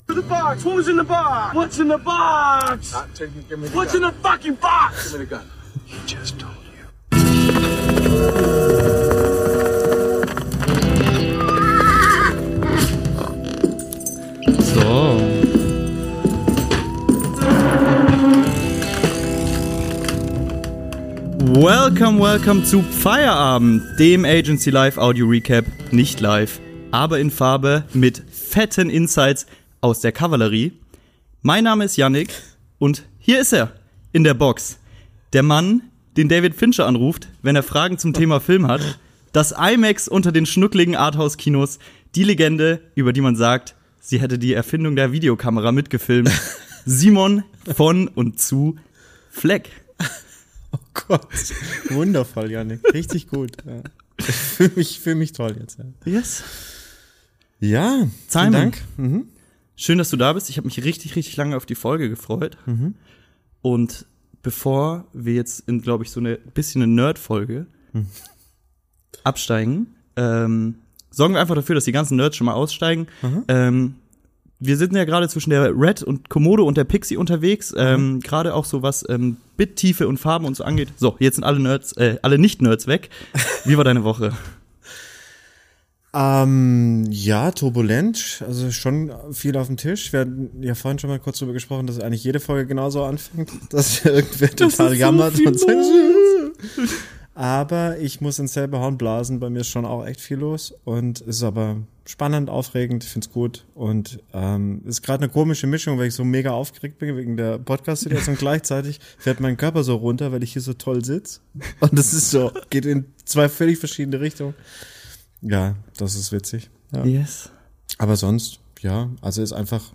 Cinema-Chat, Blenden-Bla-Bla und Schnitt-Schnack natürlich inklusive. Legt eure Füße zu den sanften Arthouse-Stimmen und Pop-Kultur-Analysen hoch und genießt es, dass ihr nie wieder normal einen Film anschauen könnt.